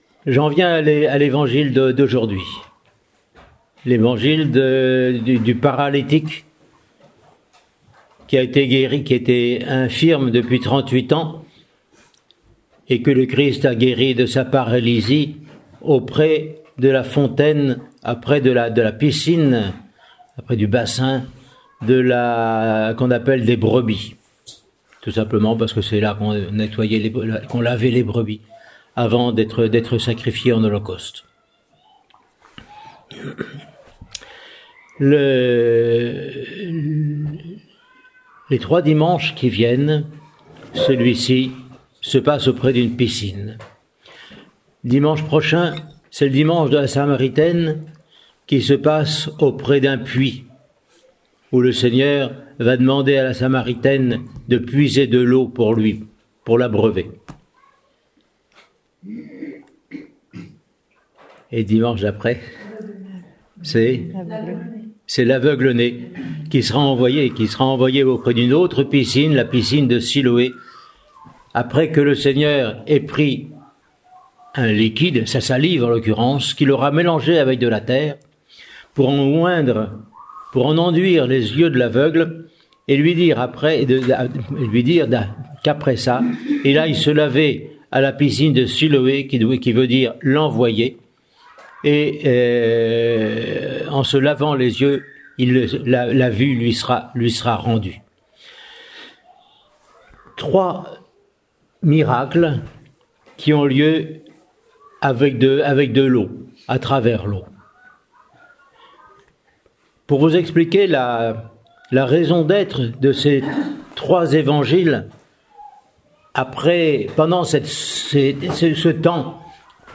Homélie pour le dimanche du paralytique :Monastère de la Transfiguration